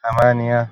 spoken-arabic-digits